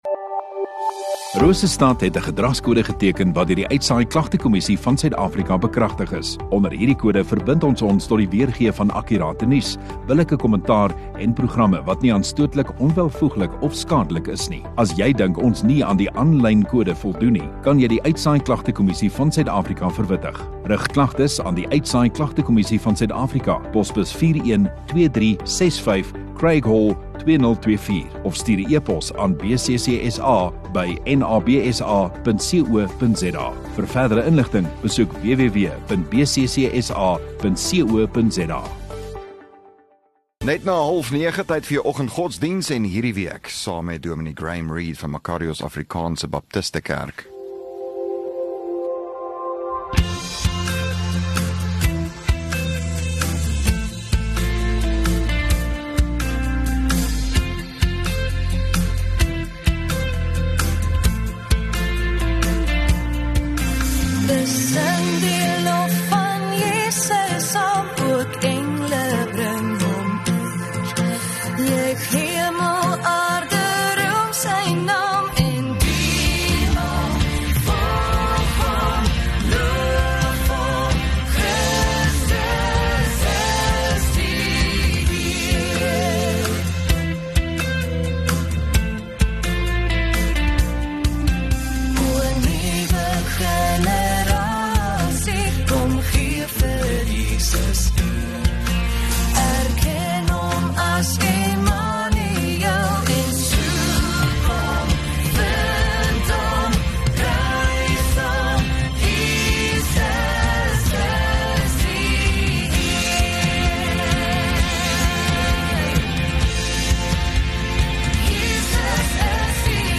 16 Apr Woensdag Oggenddiens